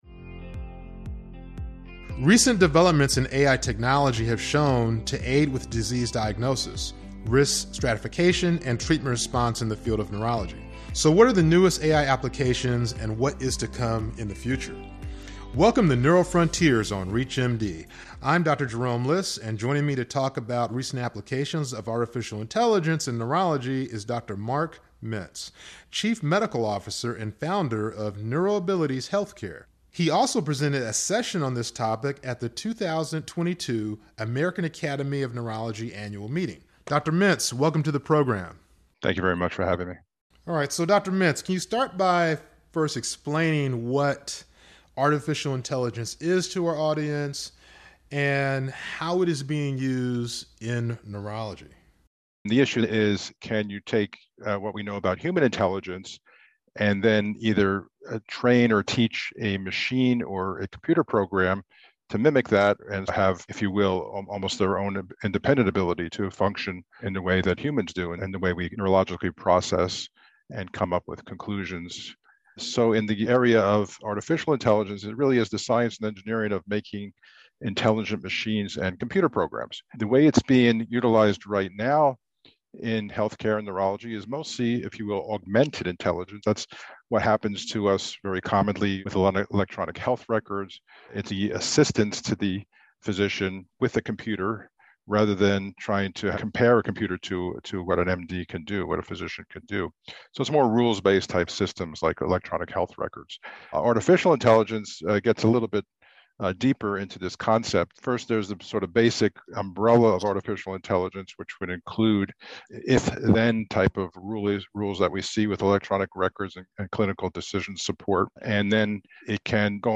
Distinguished researchers discuss their latest findings in science and biotechnology, offering an intriguing glimpse of medicine's future.